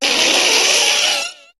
Cri de Paras dans Pokémon HOME.